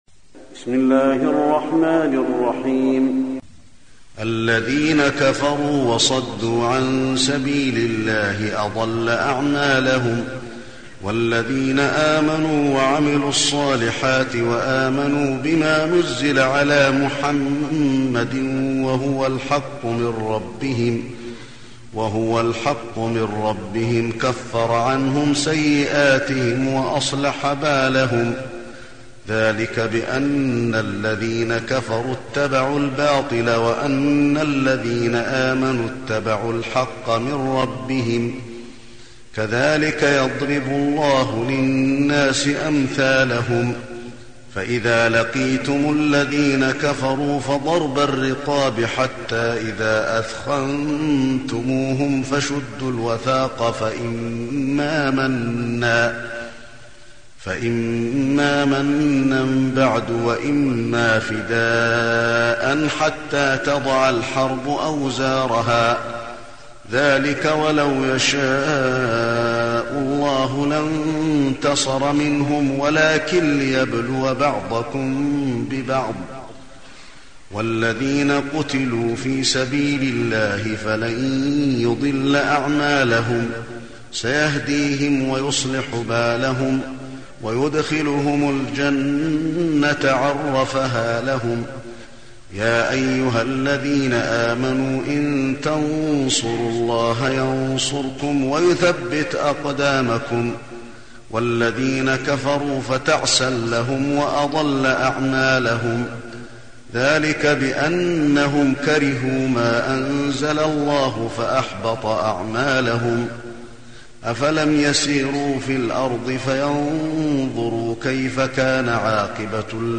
تلاوة سورة محمد بصوت الشيخ علي بن عبد الرحمن الحذيفي
تاريخ النشر ٢٥ رمضان ١٤٢٣ المكان: المسجد النبوي الشيخ: فضيلة الشيخ د. علي بن عبدالرحمن الحذيفي فضيلة الشيخ د. علي بن عبدالرحمن الحذيفي سورة محمد